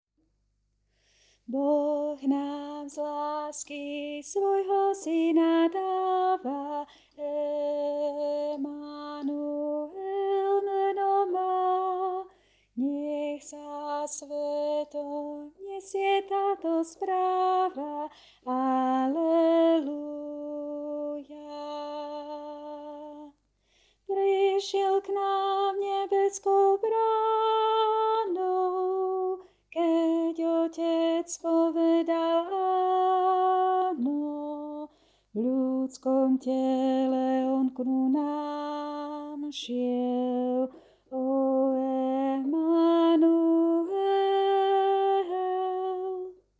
Boh_nam_z_lasky-Alt.mp3